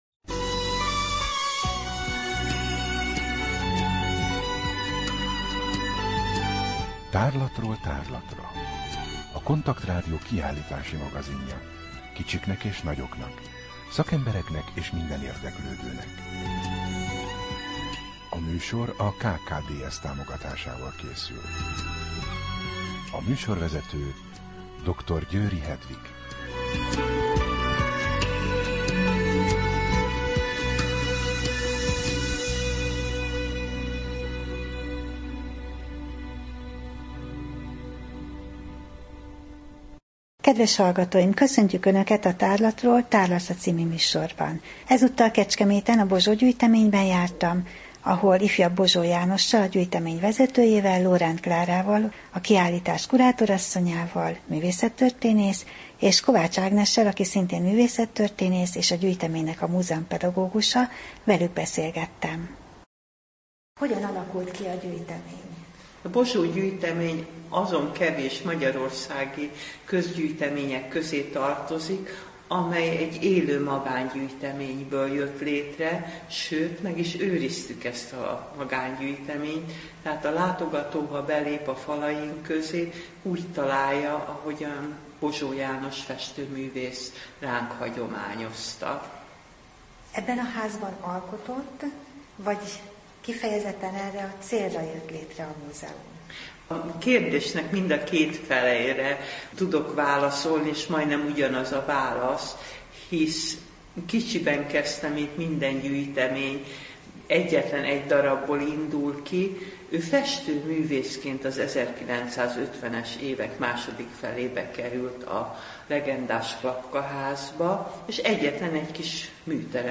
Rádió: Tárlatról tárlatra Adás dátuma: 2015, Január 8 Tárlatról tárlatra / KONTAKT Rádió (87,6 MHz) 2015. január 8.
Zenei betétek: Hortobágyi pásztordalok